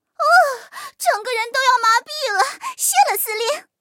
M4谢尔曼强化语音.OGG